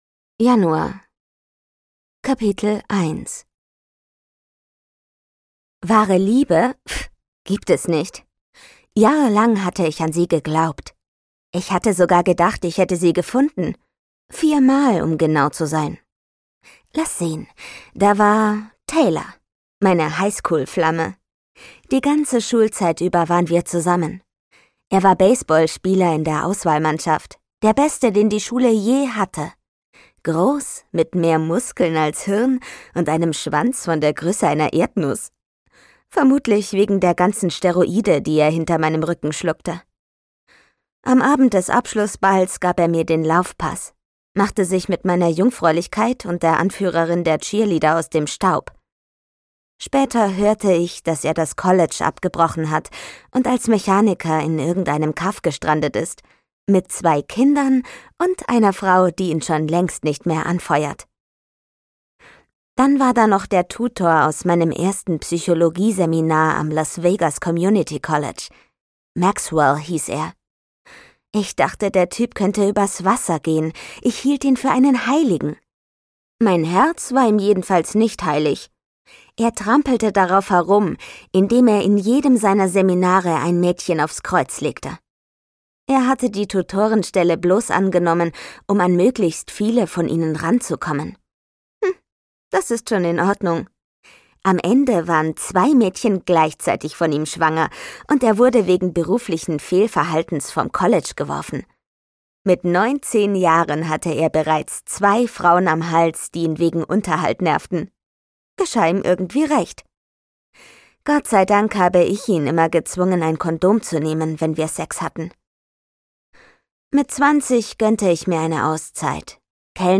Calendar Girl – Verführt (Calendar Girl Quartal 1) - Audrey Carlan - Hörbuch